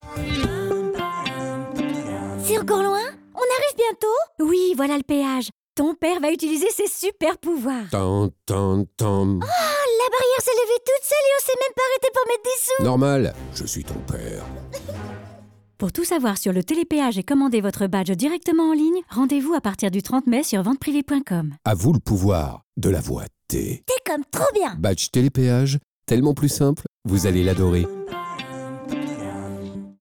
Institutionnel / E-learning